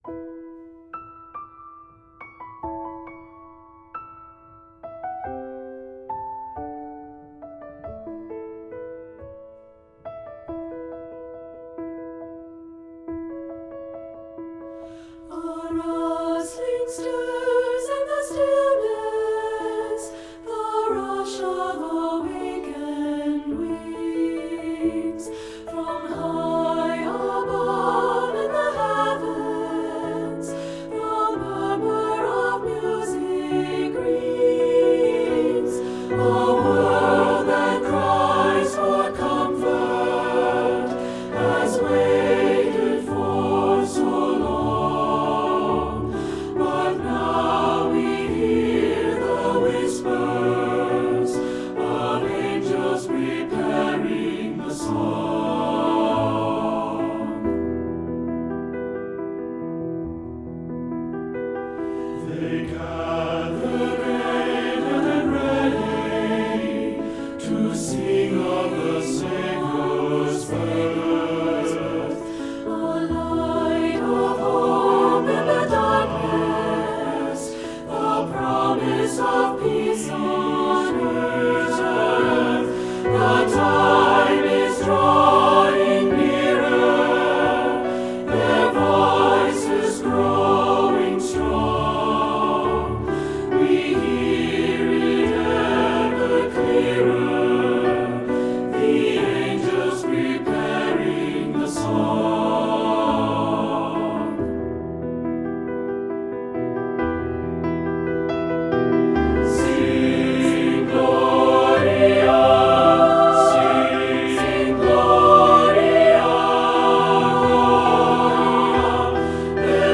Voicing: 2-part Mixed and Piano